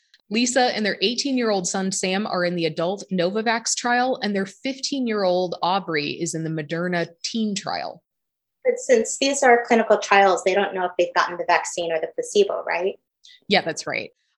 The first voice has normal room sounds but they are wearing headphones, so her voice is clear. The second voice is completely hands-free. She looks swell, but she has Zoom Voice.